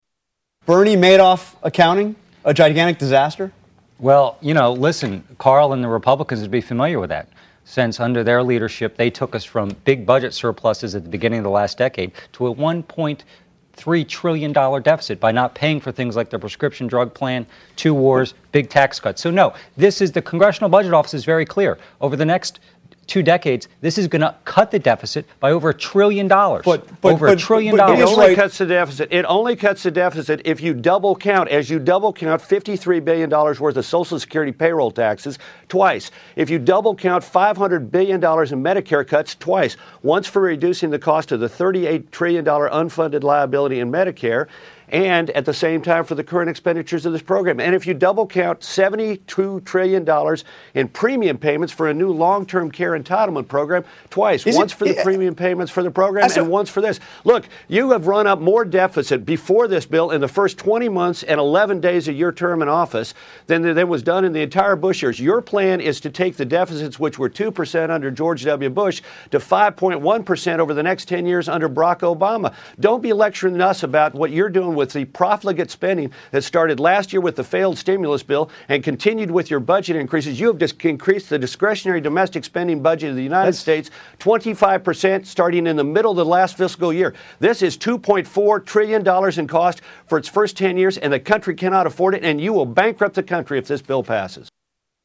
Karl Rove and David Plouffe Debate prt 1